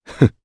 Clause_ice-Vox-Laugh_jp.wav